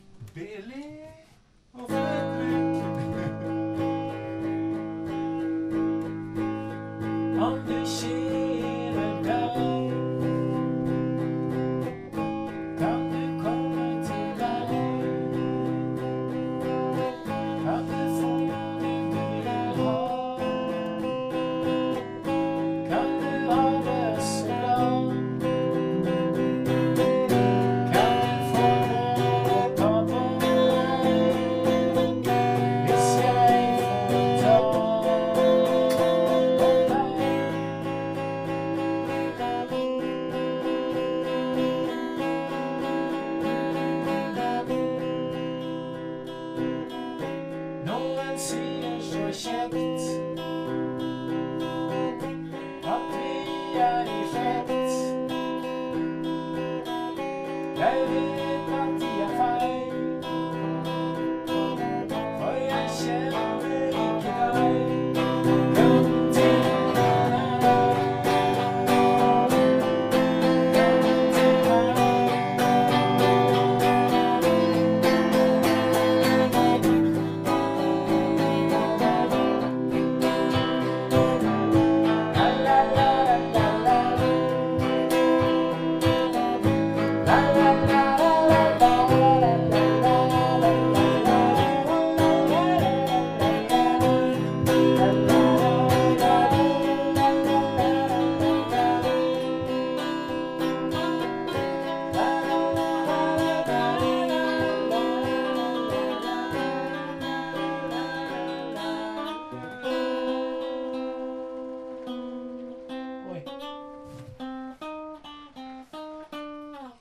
Det blev bara två stycken jam-sessions 2013, men som tur är var vi kreativa bägge gångerna, så vi fick ihop material till en väldigt bra OBSsessions-skiva, och kanske också till en jul-skiva.